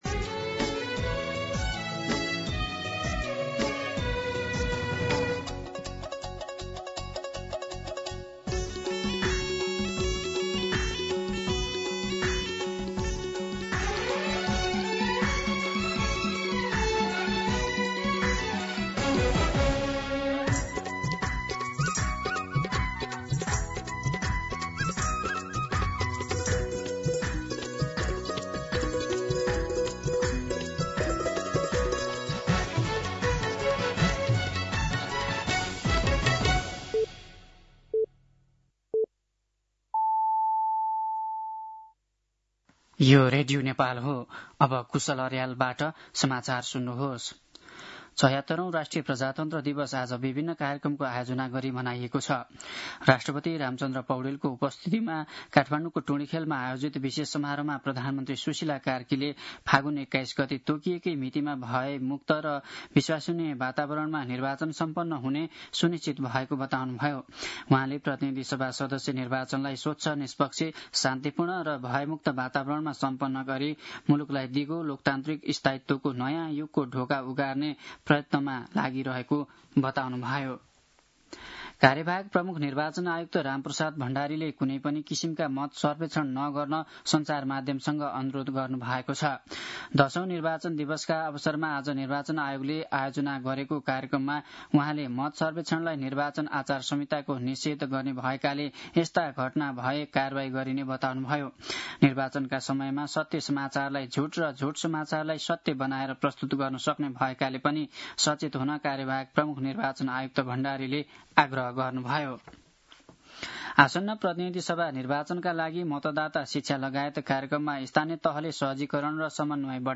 दिउँसो ४ बजेको नेपाली समाचार : ७ फागुन , २०८२